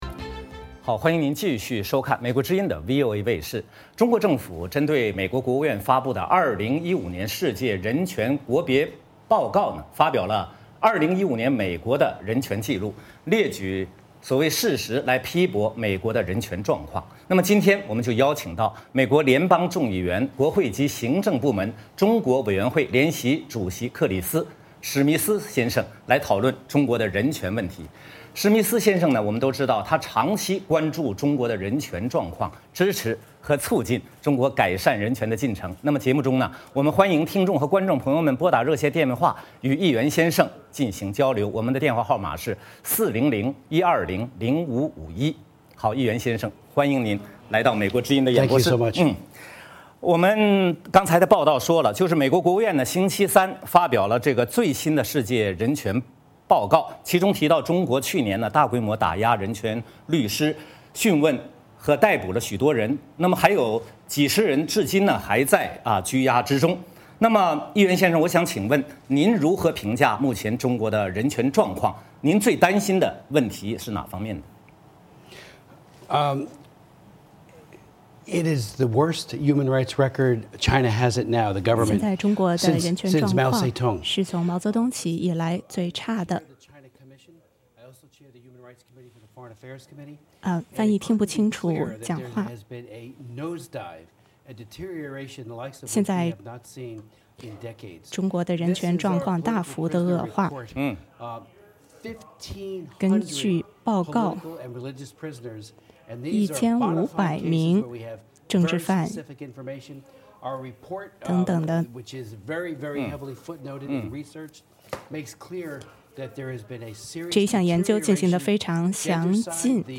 VOA卫视专访: 美国会众议员史密斯谈中国人权